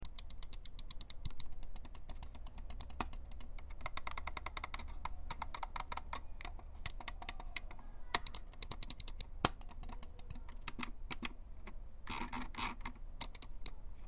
クリック音の静かさは最高
これは聞き比べてもらったほうが分かりやすいと思うので、録音しています。
別に極端にG604を強く押してるわけではありません。